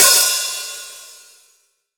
Index of /90_sSampleCDs/AKAI S6000 CD-ROM - Volume 3/Hi-Hat/STUDIO_HI_HAT